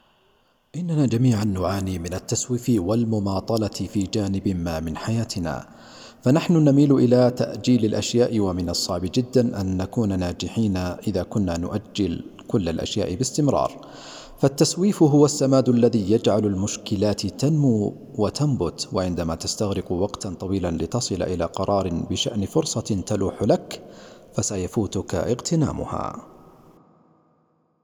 المجتمع - مجتمع صانعي المحتوى - تعليق صوتي | نفذلي
صوتي مش بس نبرة، هو إحساس، شغف، و قصة تتجسد بكلمات.